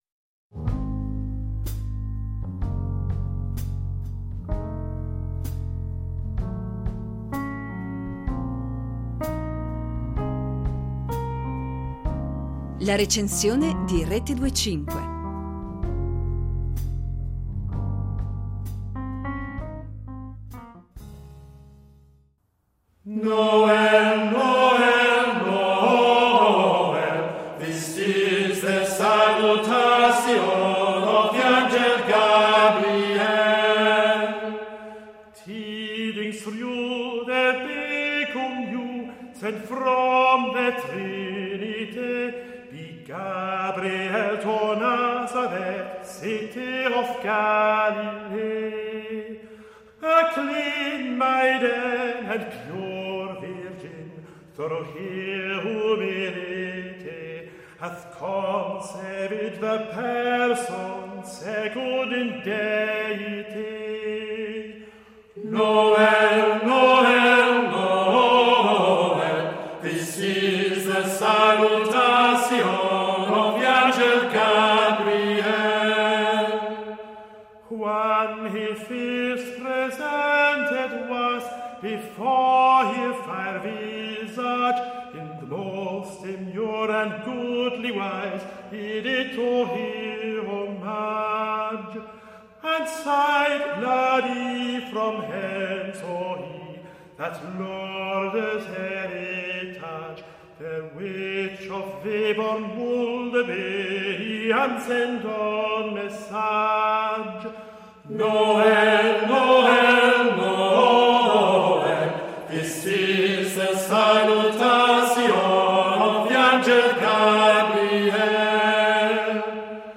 musiche tardomedievali
I canti monodici e polifonici
intensa e gioiosa dimensione spirituale